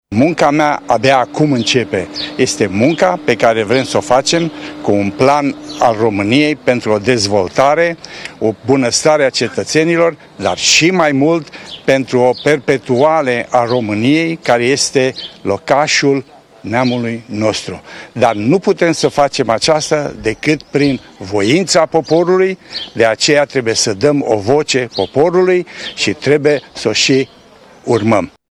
Candiadtul independent la alegerile prezidențiale John-Ion Banu-Muscel a declarat azi, la ieșirea de la vot, că cetățenii României ”au nevoie de o schimbarecare poate să fie realizată prin vocea poporului și în felul acesta clasa politică să fie prima schimbată.
John-Ion Banu-Muscel a votat la o secție din capitală: